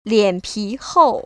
[liănpí hòu] 리앤피호우  ▶